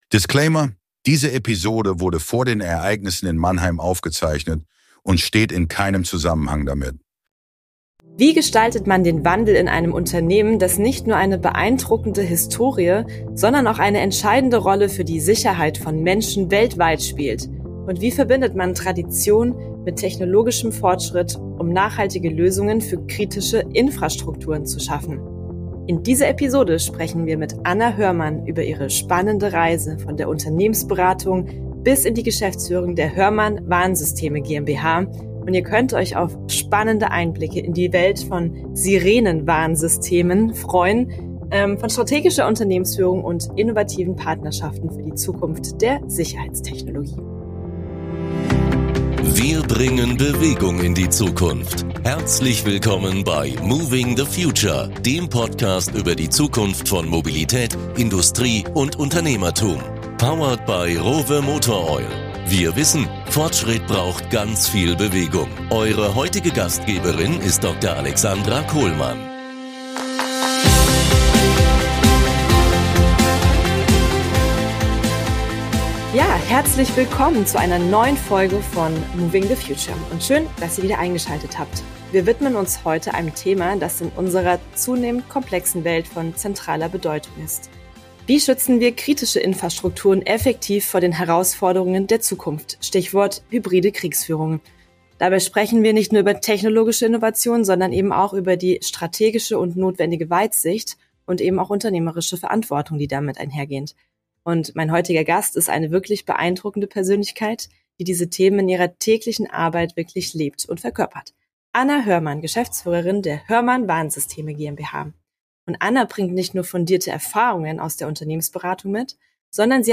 Doch wie schaffen wir es, die Bevölkerung besser zum Thema Zivilschutz abzuholen? Wie gelingt es, bürokratische Hürden zwischen Wirtschaft und Behörden abzubauen und die Zusammenarbeit bei der kritischen Infrastruktur zu verbessern? Ein Gespräch, das weit über den Tellerrand von Unternehmensführung hinaus geht.